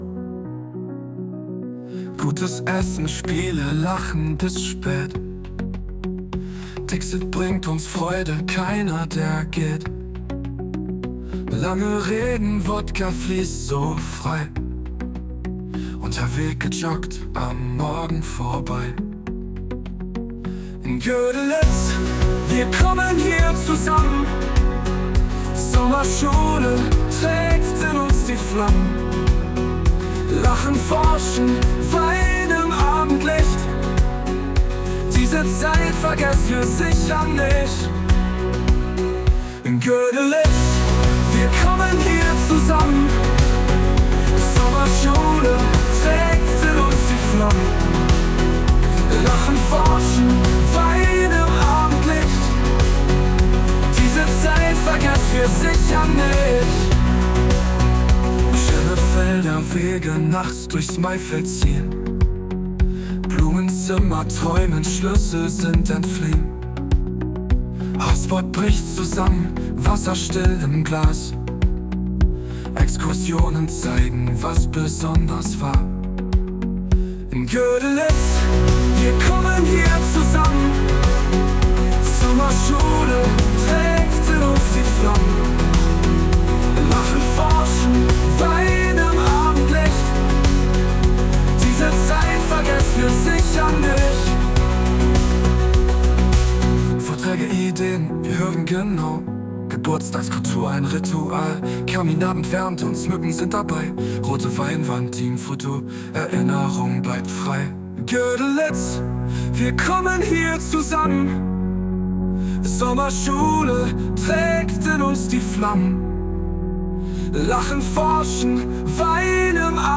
(Dieser Song wurde mit Suno AI (kostenlose Version) generiert.